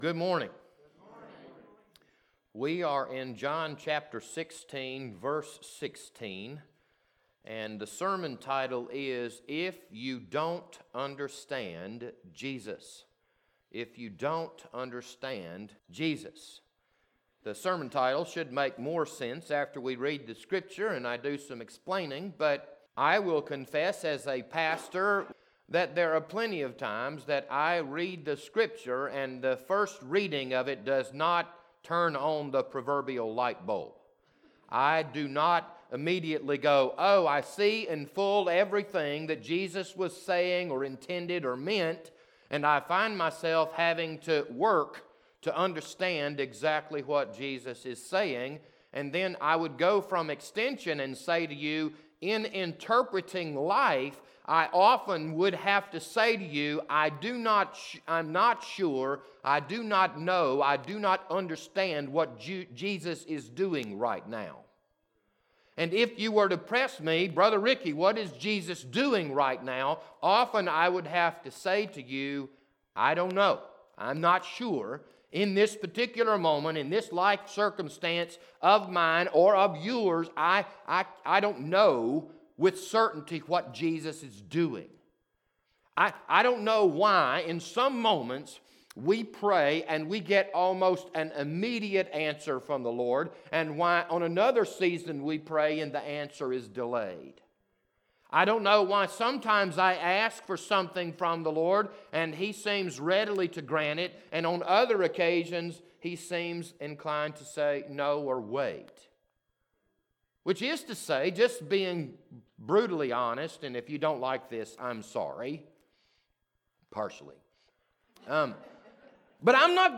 This Sunday morning sermon was recorded on March 21st, 2021.
Sunday Morning Sermons